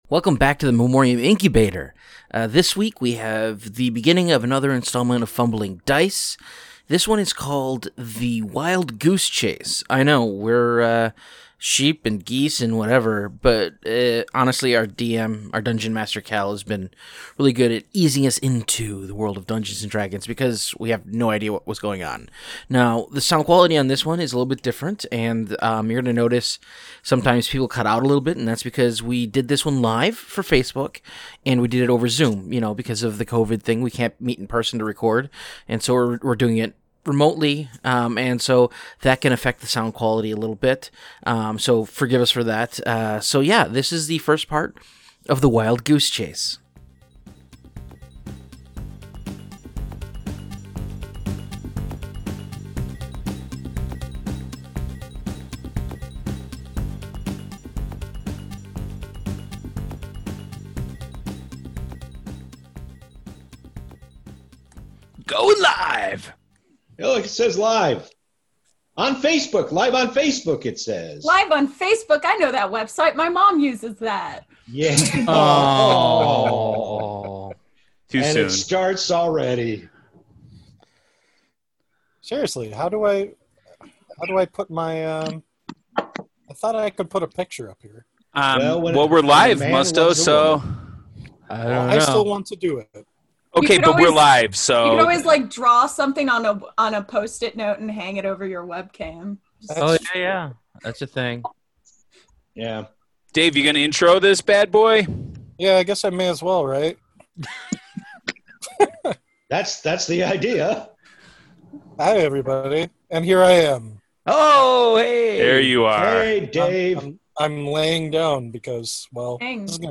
Join Little Orphan Annie, Luigi Mario, a Shirtless Alex Jones, Axel Foley, and Reverend Jim as they bound off on a Dungeons and Dragons adventure that involves thugs that are geese. Recorded via Zoom due to the Rona, Fumbling Dice is the newest podcast produced by Memoriam Development.